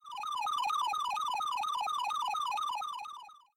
复古效果 " 复古随机声音02
描述：听起来像一个复古的生物经过:) 还可以查看我的Patreon频道的独家音效，chiptune音乐和循环：https：
声道立体声